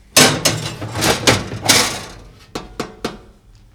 Oven Cleaning Sound
household